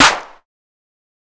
TC3Clap7.wav